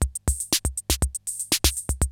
CR-68 LOOPS4 1.wav